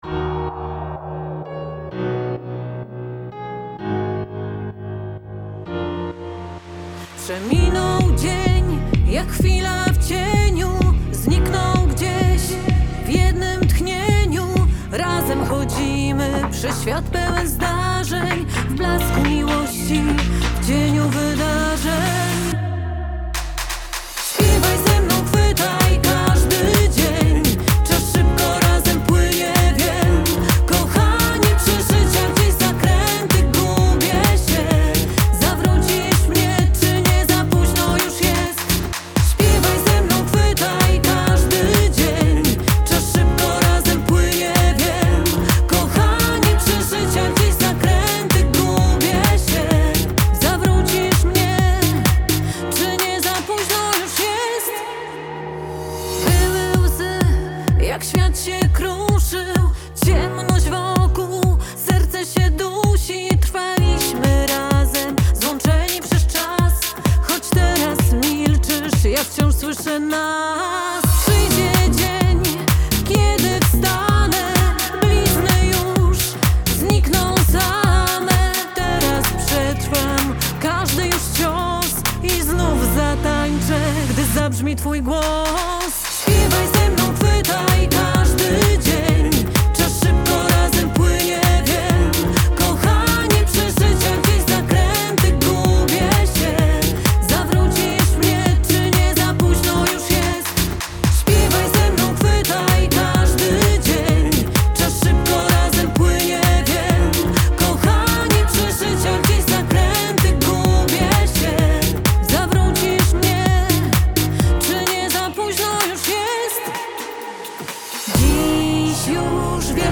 Singiel (Radio)
Rozterki życiowe w pozytywnym, popowym tonie.
Współczesny slap-house